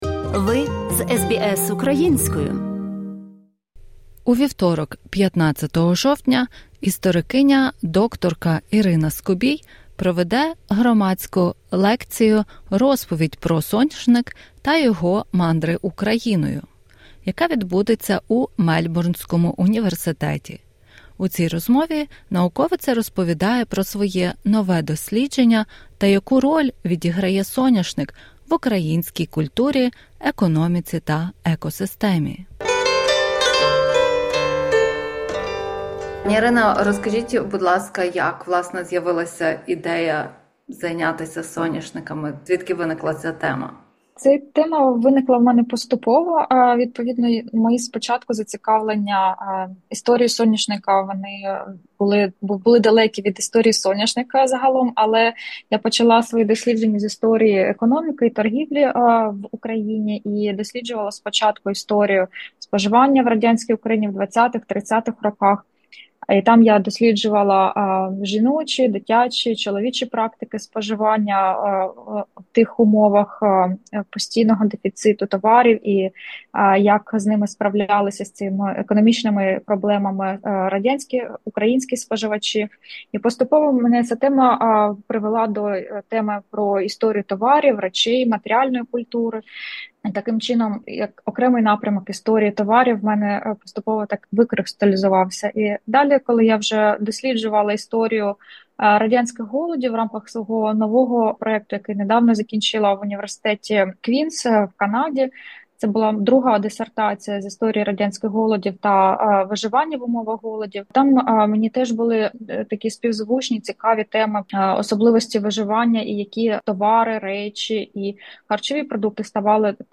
У цій розмові науковиця розповідає про своє нове дослідження та яку роль відігряє соняшник в українській культурі, економіці та екосистемі.